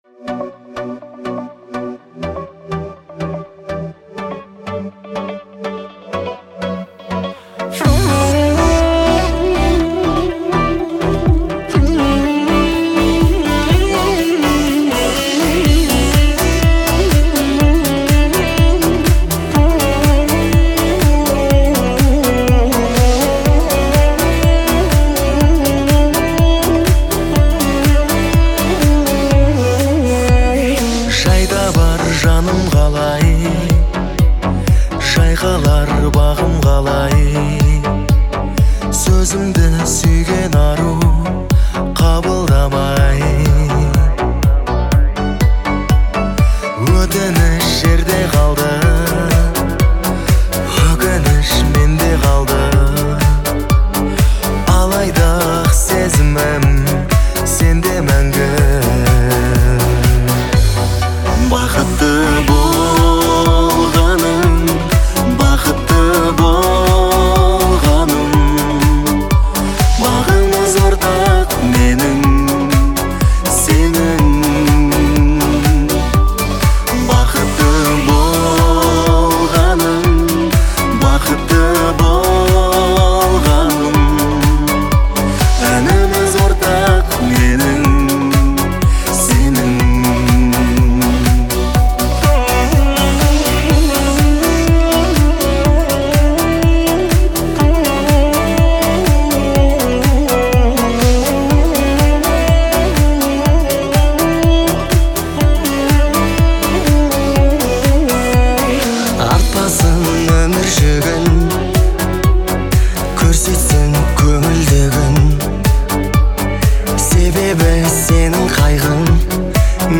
это яркий пример казахского поп-фолка